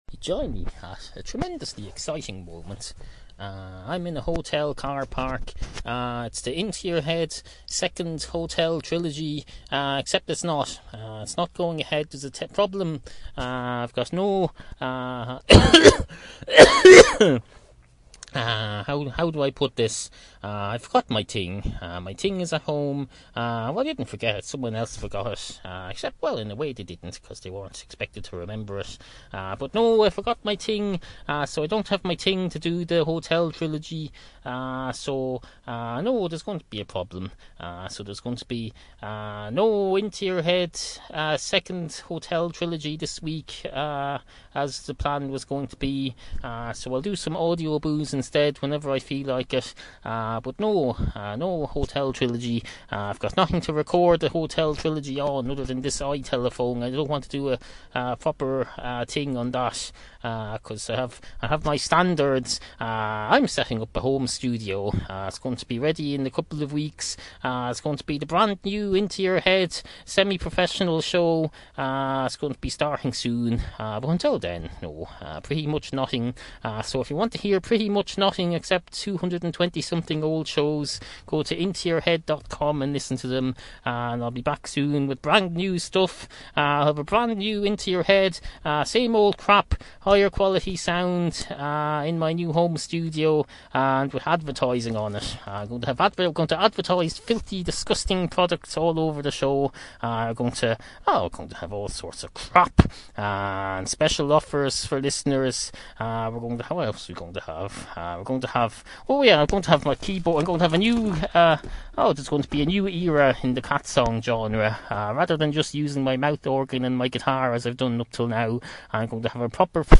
New listeners are reeuested to start with later episodes, like the 500s or, even better, the 800s. nd Hotel Trilogy – Parts I through IV Excuse the variable sound quality, but here are some recent recordings from the comfort of a hotel room somewhere in Ireland.